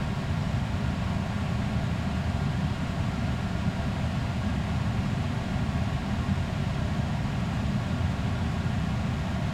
background_air_vent_fan_loop_03.wav